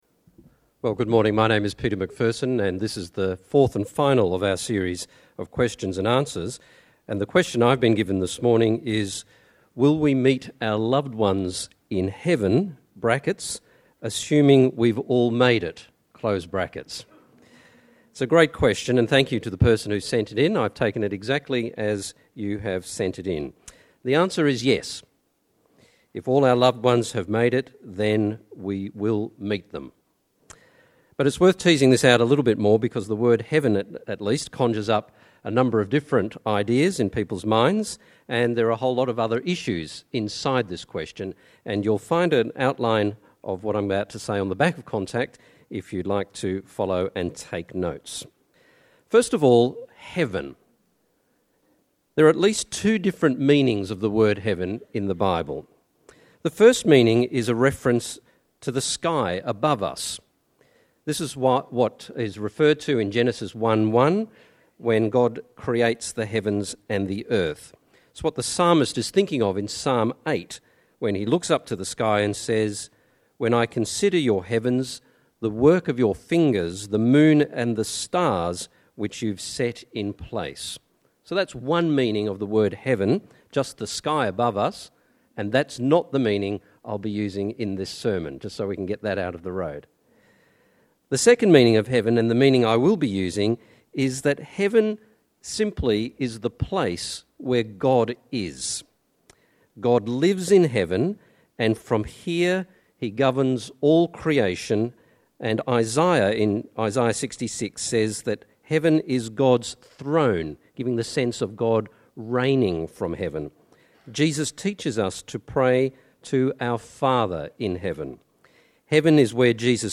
Download Download Bible Passage 1 Corinthians 15:35-58 In this sermon